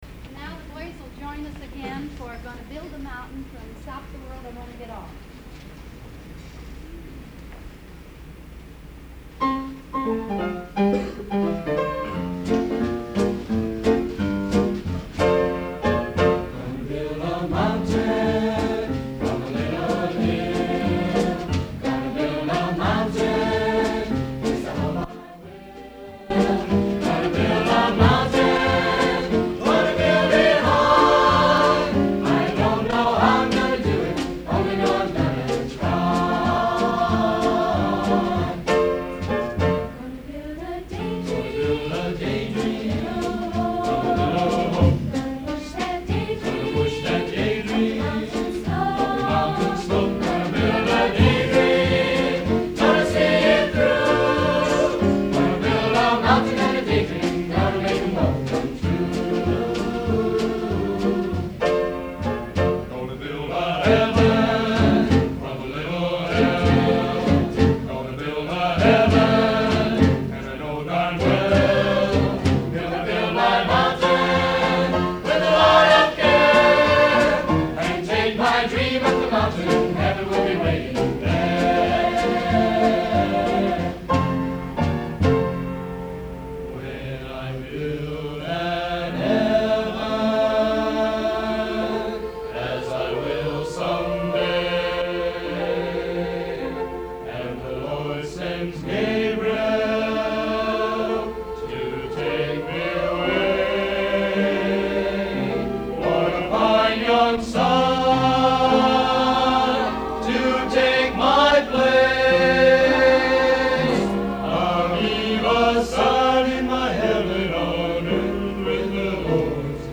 Swing Choir
Swing Choir Performance
Recorded at the Elks Club for St Joseph County Nurses Assoc.